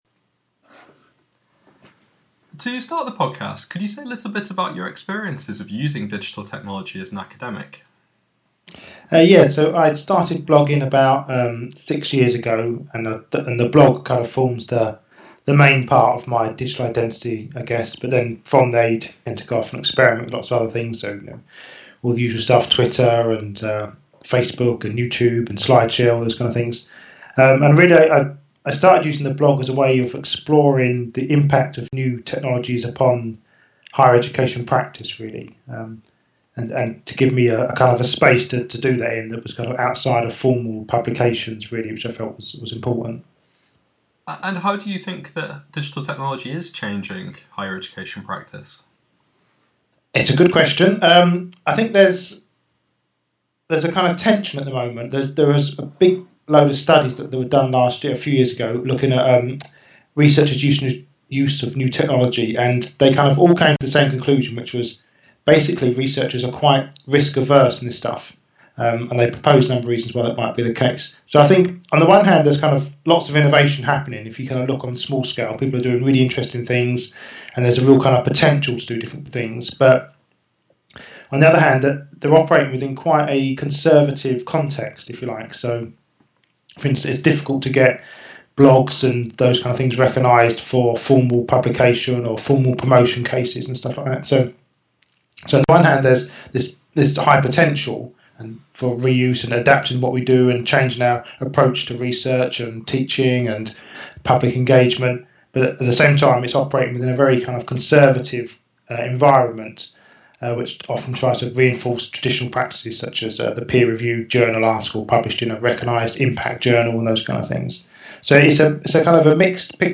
The Transformation of Academic Practice – Interview